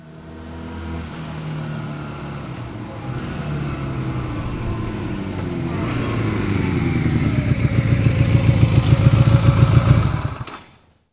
Index of /90_sSampleCDs/AKAI S6000 CD-ROM - Volume 6/Transportation/MOTORCYCLE
400-BACK.WAV